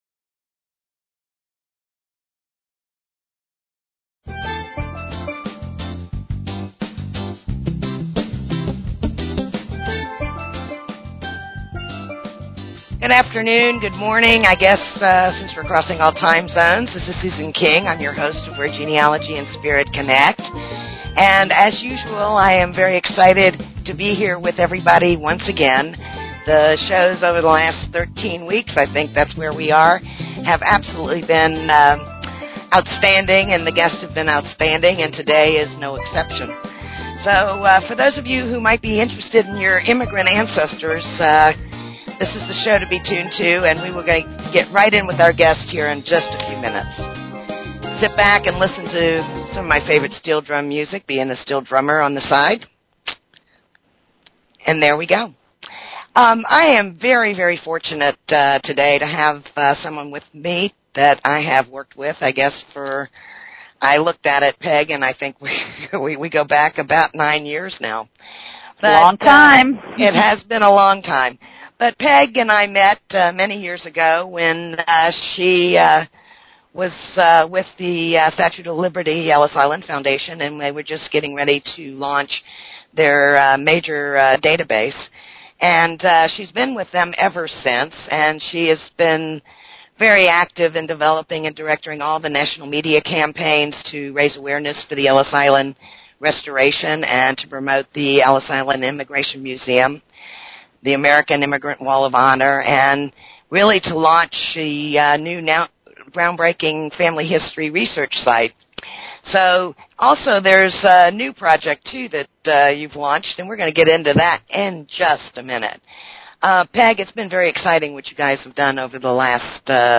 Talk Show Episode, Audio Podcast, Where_Genealogy_and_Spirit_Connect and Courtesy of BBS Radio on , show guests , about , categorized as